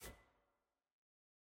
sfx-jfe-ui-generic-hover.ogg